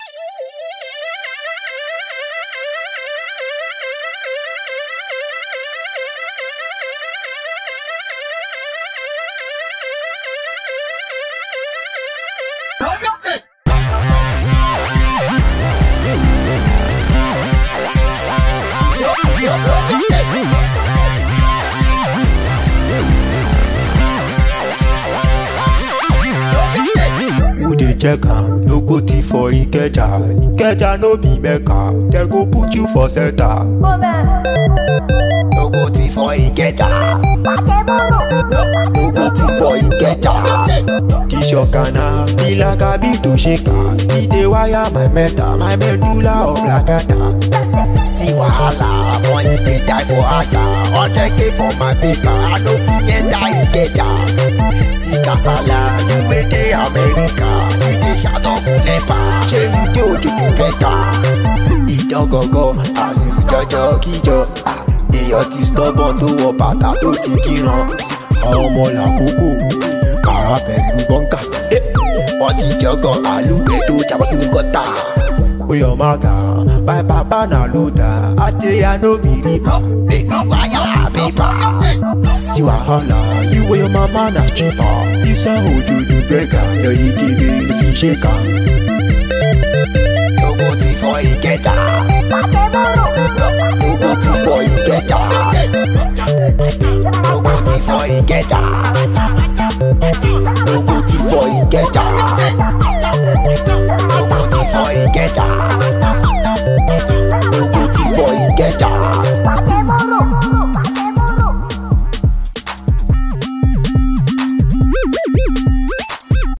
emotionally charged
With its infectious beat and captivating vocals